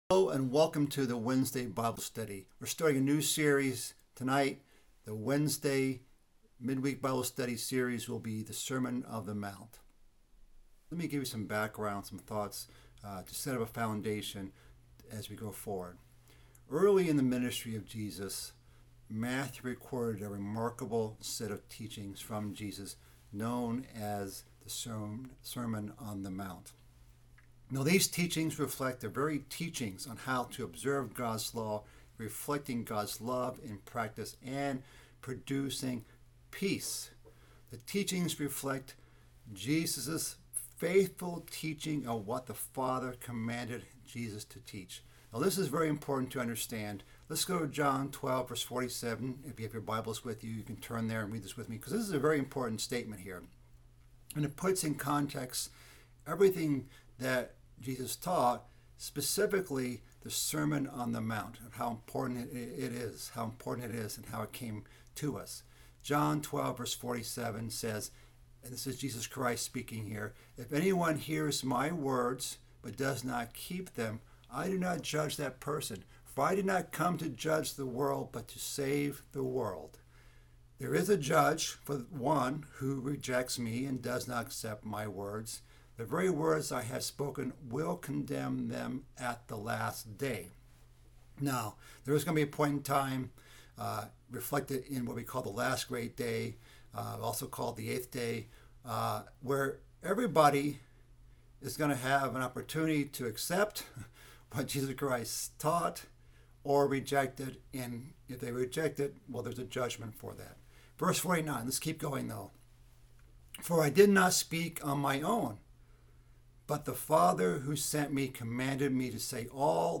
Bible Study - Sermon on the Mt. Part 1 - Matthew 5:1-3